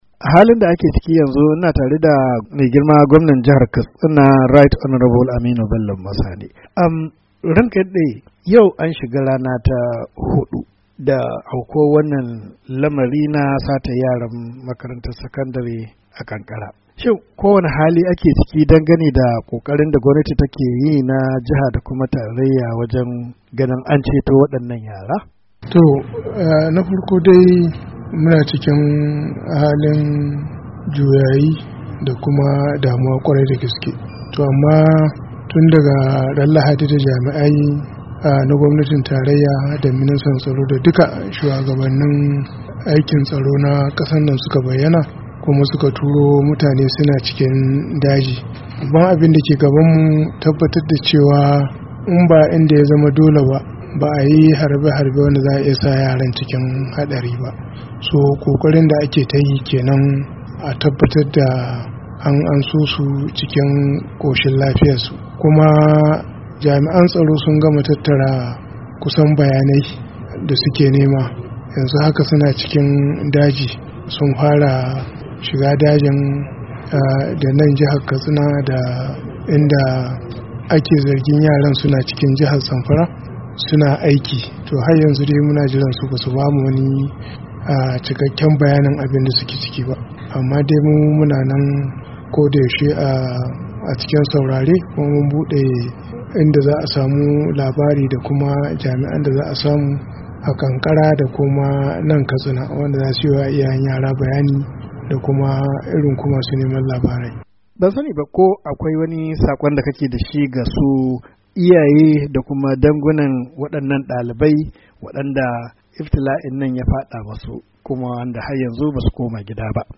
Hira da gwamna Aminu Bello Masari:2:58"